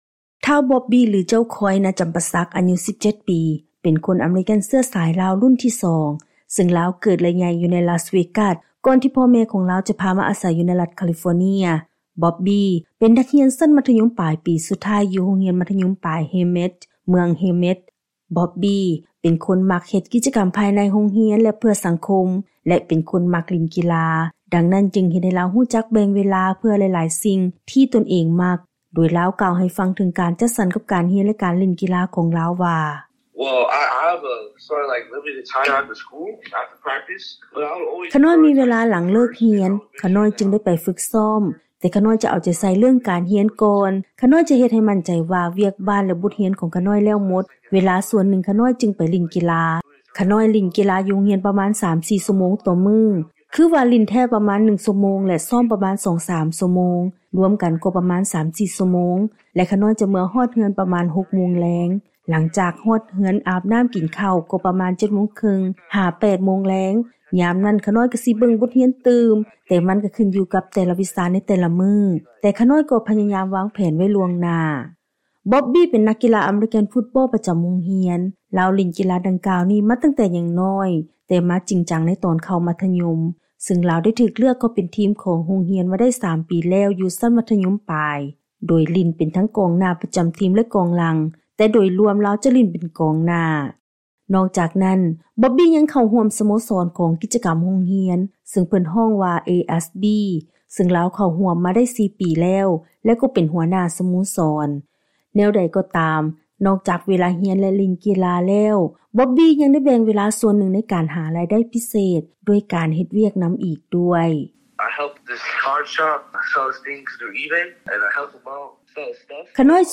ຟັງລາຍງານ ການສໍາພາດ